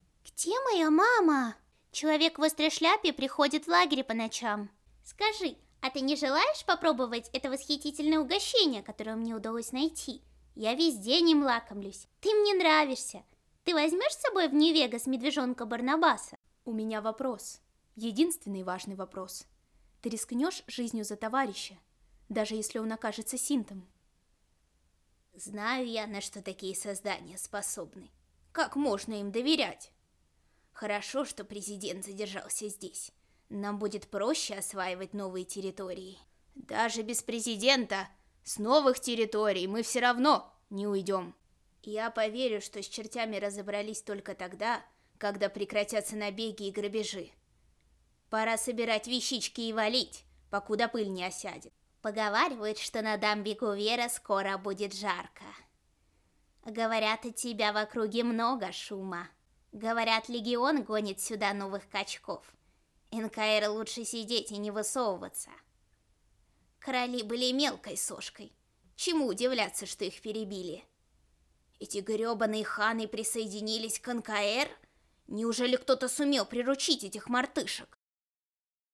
Могу озвучивать детей (как мальчиков,так и девочек), подростков, молодых и зрелых девушек.
Микрофон Samson C01U Pro, компьютер Macbook, специальное помещение для записи